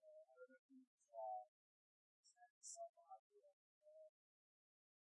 外星人的声音
听起来像机器人噪音或外星人的噪音。
标签： 飞船 语音 机器人 声音效 外星人 机器人 太空机器人的声音 SFX 科幻 OWI 语音
声道立体声